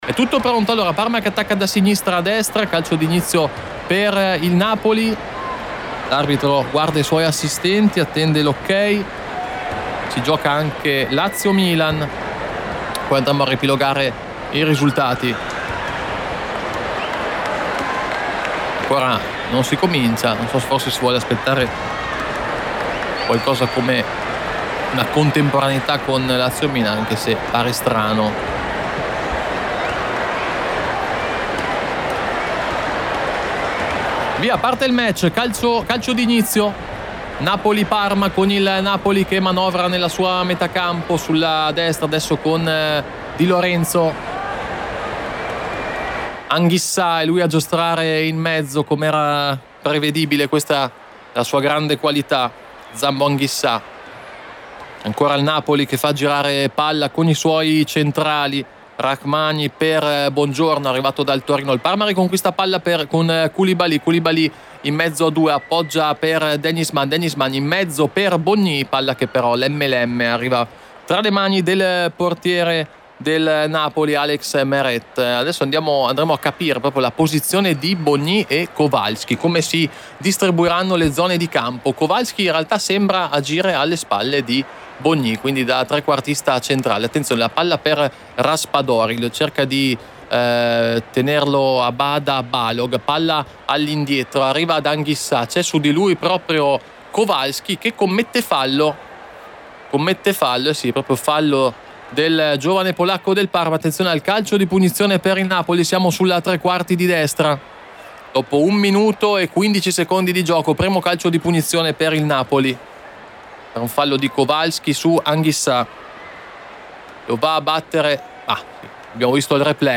Radiocronache Parma Calcio Napoli - Parma 1° tempo - 31 agosto 2024 Aug 31 2024 | 00:50:09 1x 00:00 / 00:50:09 Subscribe Share RSS Feed Share Link Embed